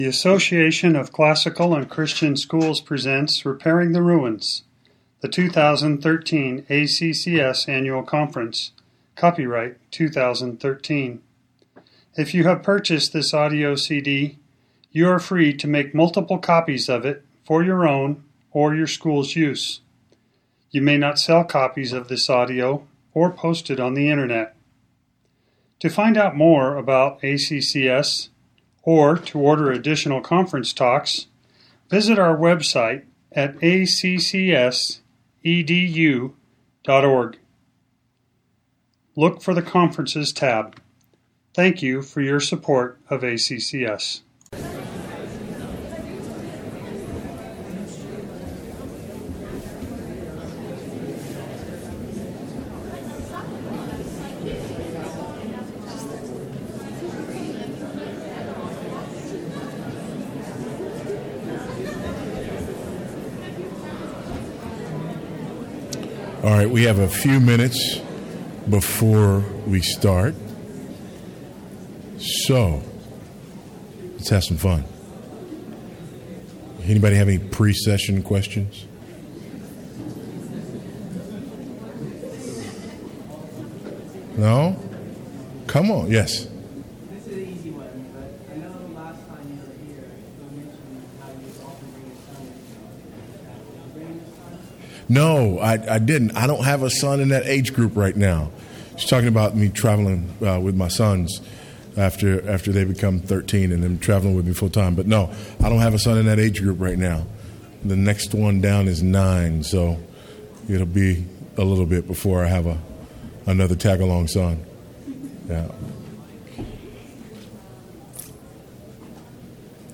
2013 Workshop Talk | 1:05:42 | All Grade Levels, Culture & Faith
The Association of Classical & Christian Schools presents Repairing the Ruins, the ACCS annual conference, copyright ACCS.